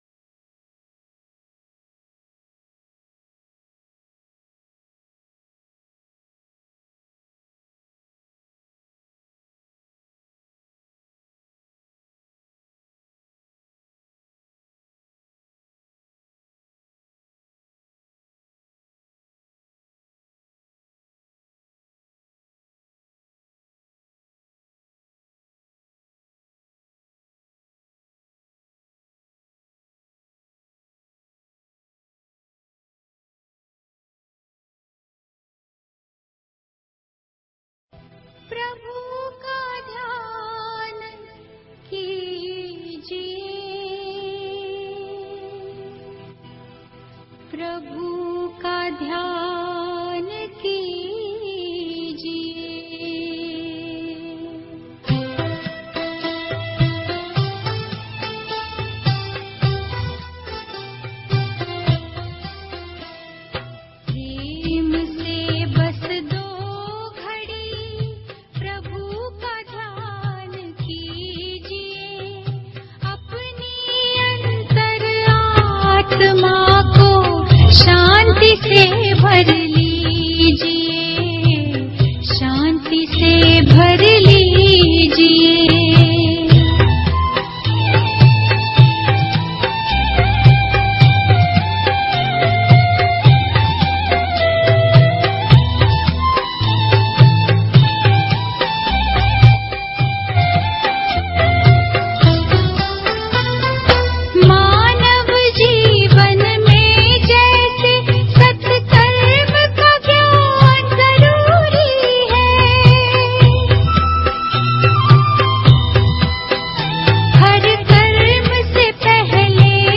B K Shivani Lectures
Shivani Lecture - Day 2.mp3